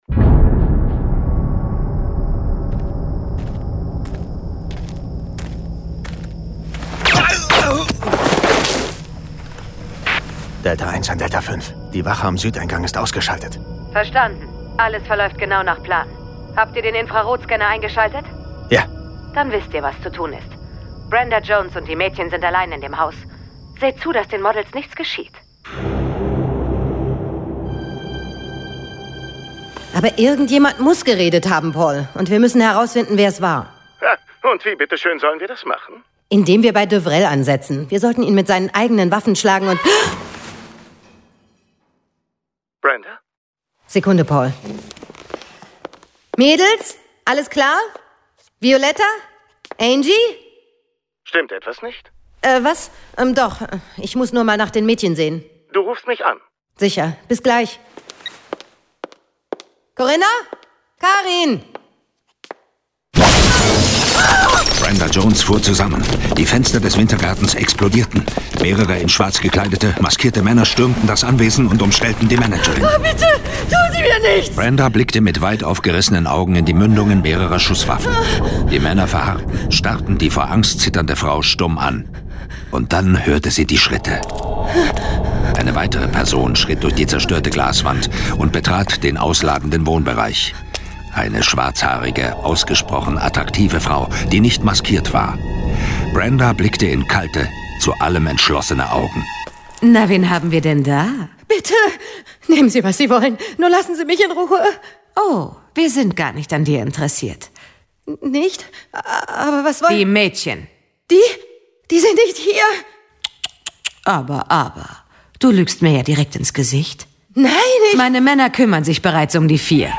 John Sinclair - Folge 51 Mannequins mit Mörderaugen. Hörspiel.